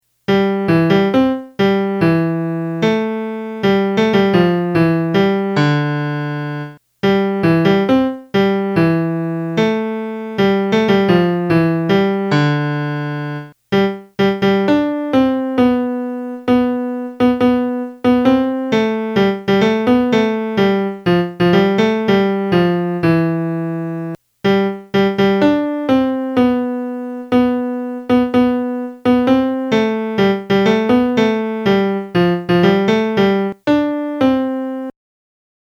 sul-ponte-di-bassano-melody.mp3